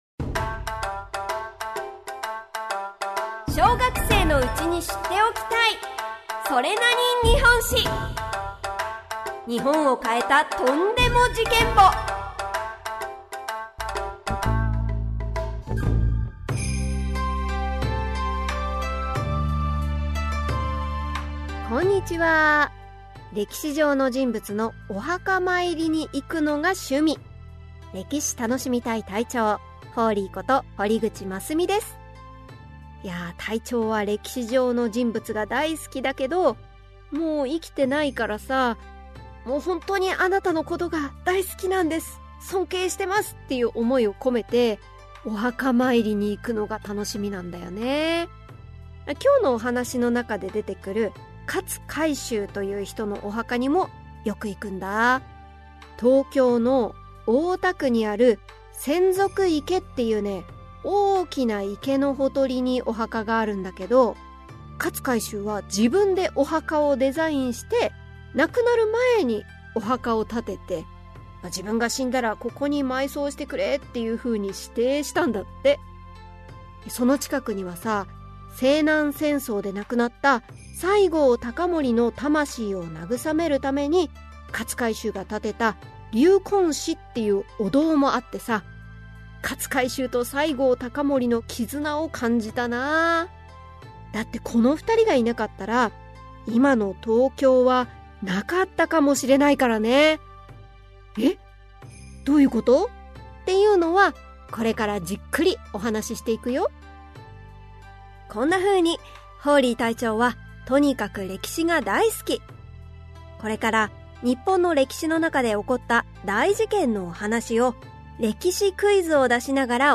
[オーディオブック] それなに？日本史 Vol.26 〜戊辰戦争〜江戸城無血開城〜